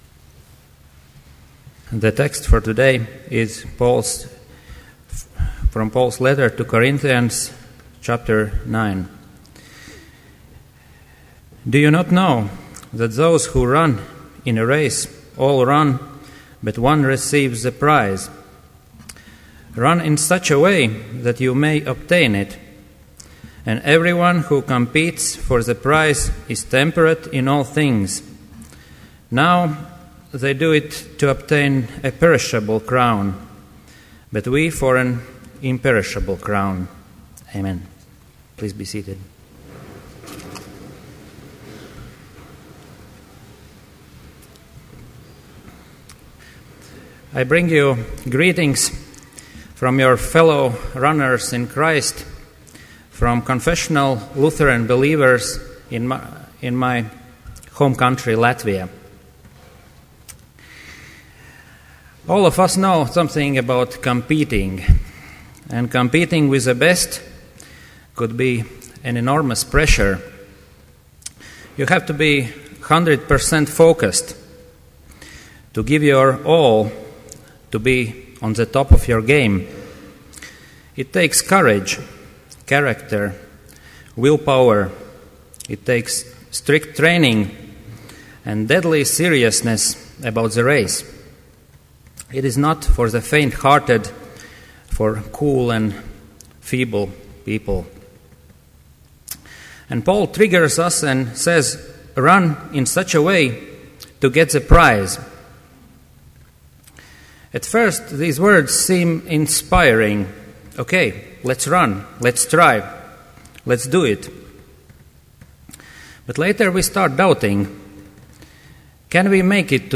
Complete Service
• Prelude
• Homily
This Chapel Service was held in Trinity Chapel at Bethany Lutheran College on Monday, October 15, 2012, at 10 a.m. Page and hymn numbers are from the Evangelical Lutheran Hymnary.